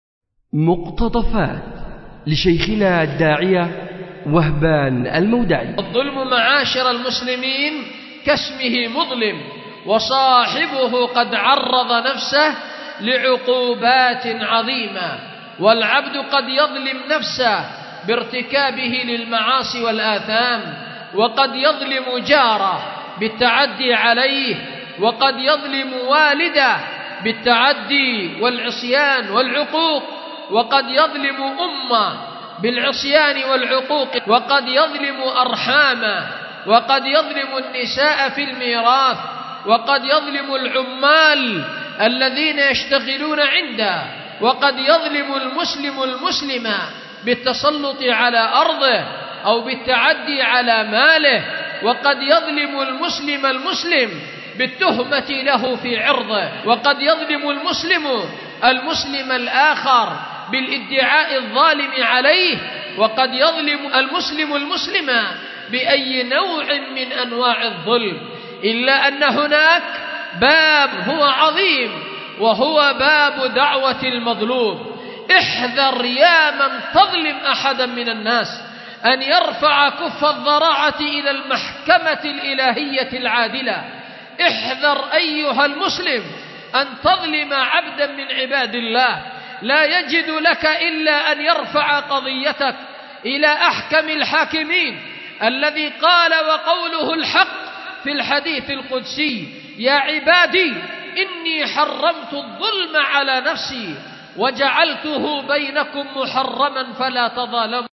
أُلقي بدار الحديث للعلوم الشرعية بمسجد ذي النورين ـ اليمن ـ ذمار ـ 1444هـ